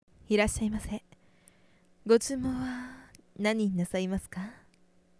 ネット声優に50のお題はこちらお借りしました
07.いらっしゃい 店員さんっぽく・・・